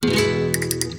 Castanets:
Артефакты все те же, что и для Prosoniq TimeFactory, только чириканье на ударах заметно усилено.
Castanets_Nu_075.mp3